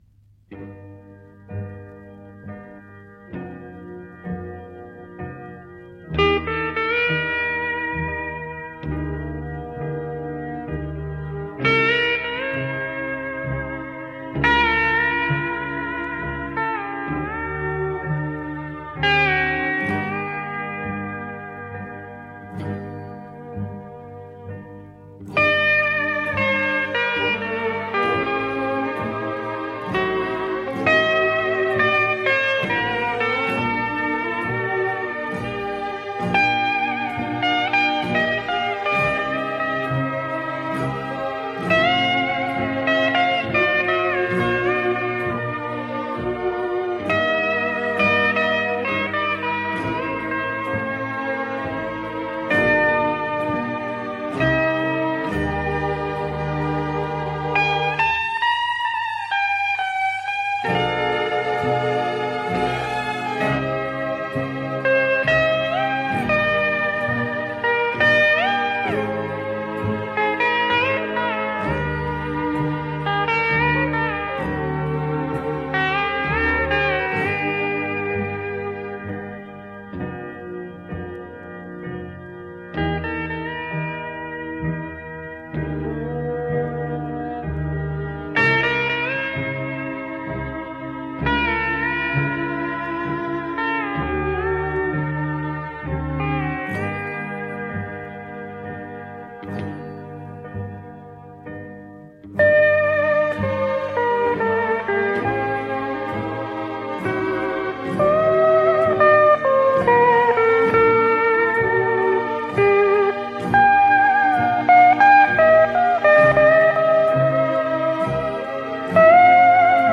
radiomarelamaddalena / STRUMENTALE / GUITAR HAWAY / 1 /